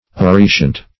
Appreciant \Ap*pre"ci*ant\, a.